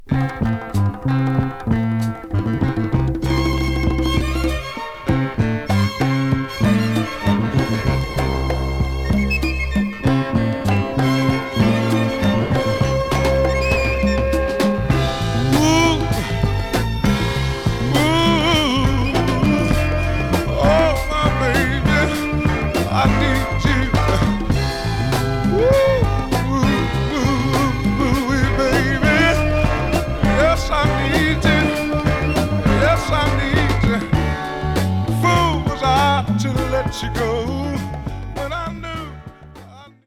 Genre: Soul, Soul/Funk